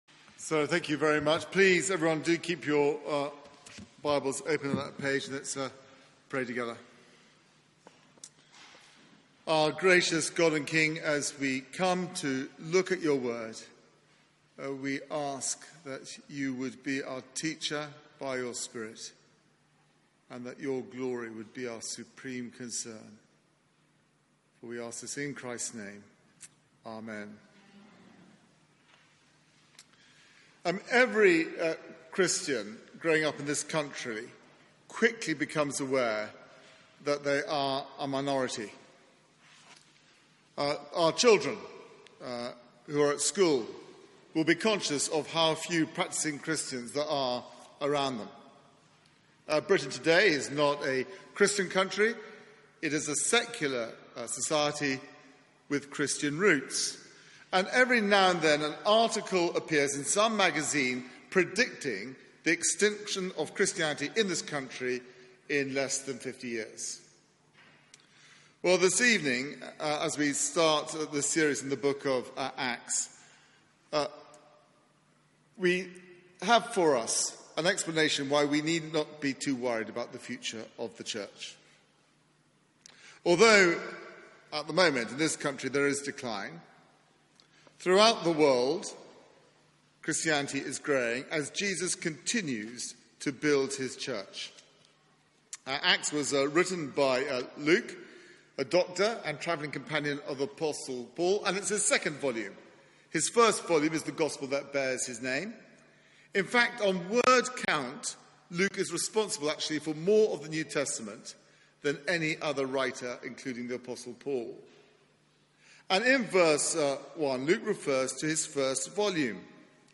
Media for 4pm Service on Sun 03rd Jun 2018 16:00 Speaker
Theme: Master Plan Sermon